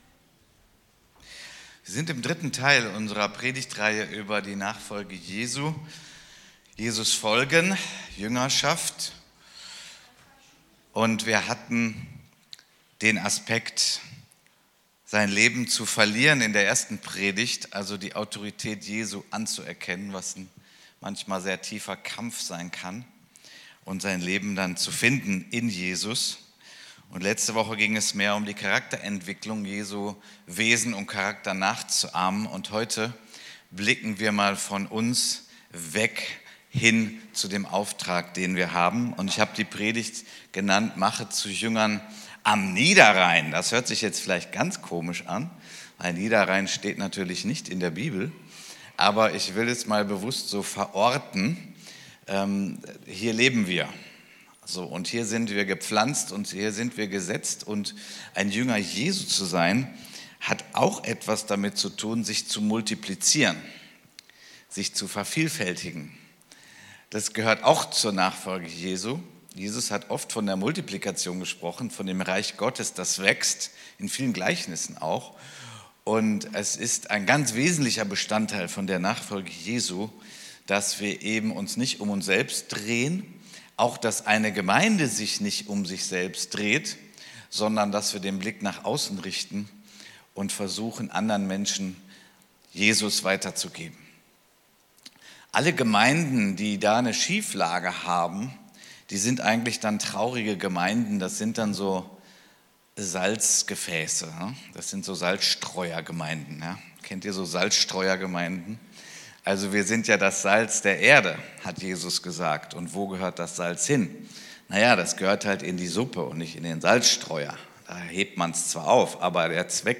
Predigten aus Krefeld – Evangeliumshaus